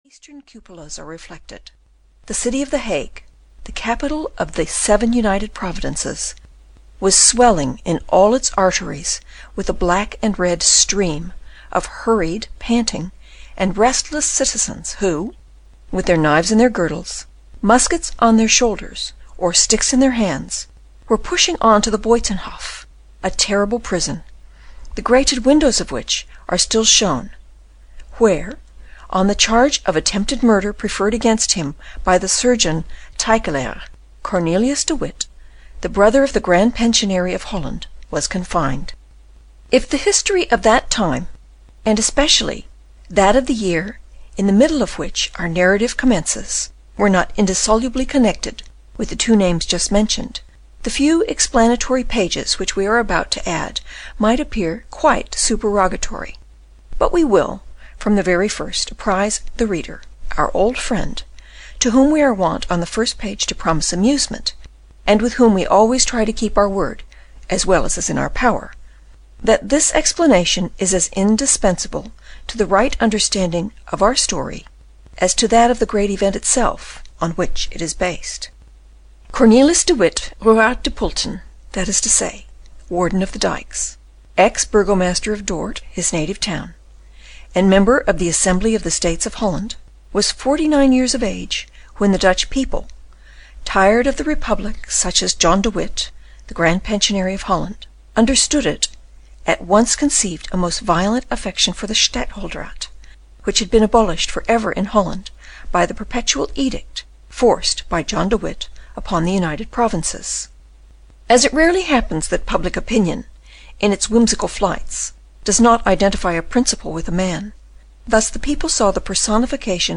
The Black Tulip (EN) audiokniha
Ukázka z knihy